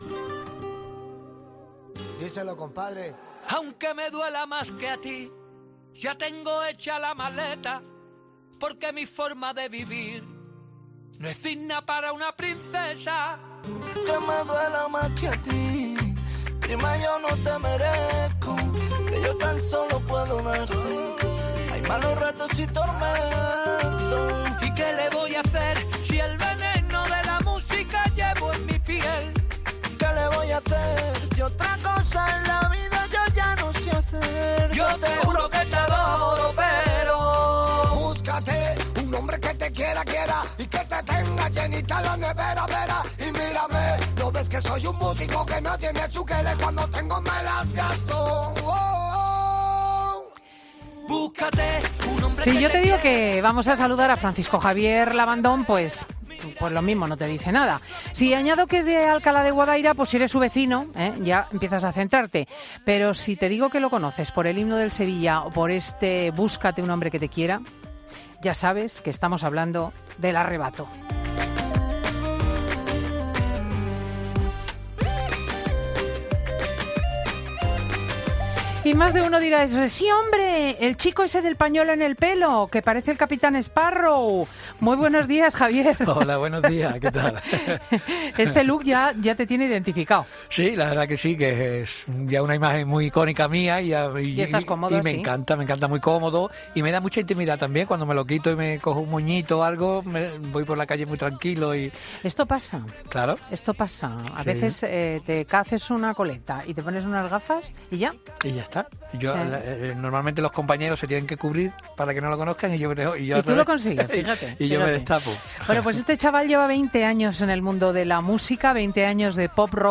En el estudio ha estado con nosotros Francisco Javier Labandón, igual no te dice nada.
Ha estado con Cristina Lopez Schliccting en el estudio para celebrar sus veinte años en el mundo de la música con un disco en el que revisa todas sus canciones acompañado de grandes amigos con Miguel Poveda, Pastora Soler o Rosana entre otros.